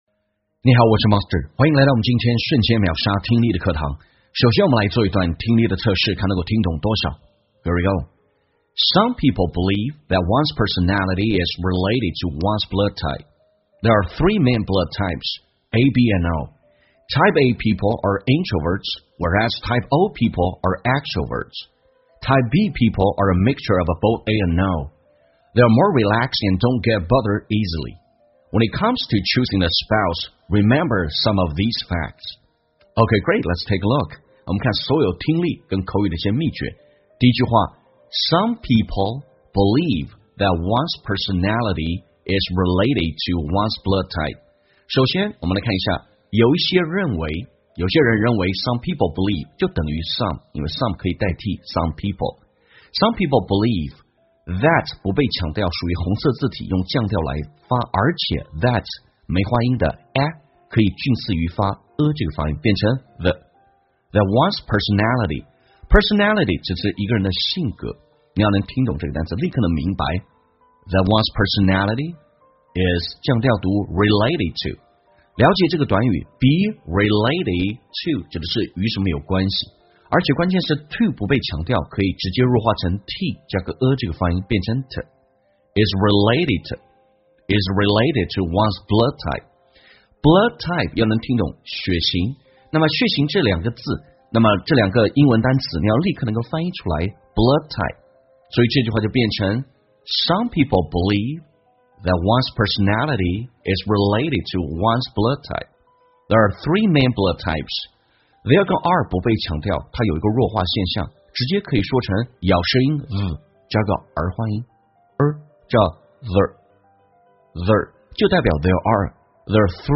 在线英语听力室瞬间秒杀听力 第595期:血型和个性的听力文件下载,栏目通过对几个小短句的断句停顿、语音语调连读分析，帮你掌握地道英语的发音特点，让你的朗读更流畅自然。